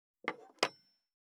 218,机に物を置く,テーブル等に物を置く,食器,
コップ効果音物を置く
コップ